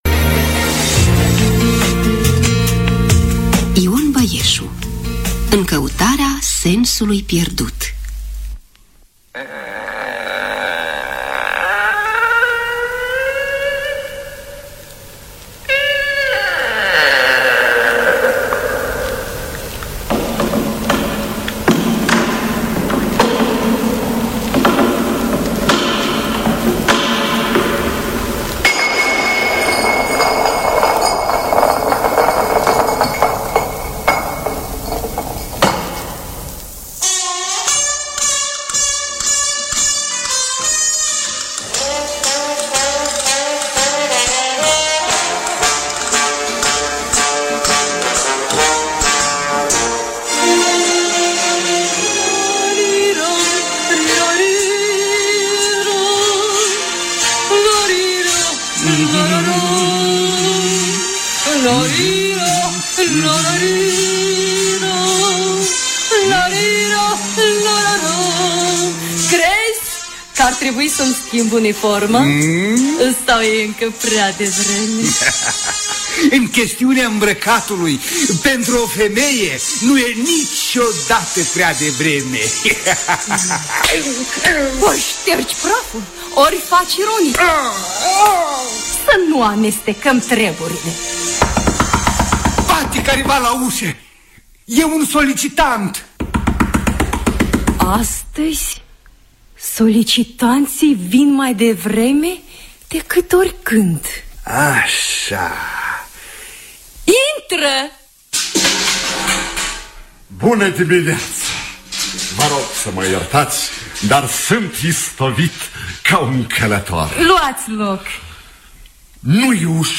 În căutarea sensului pierdut de Ion Băieșu – Teatru Radiofonic Online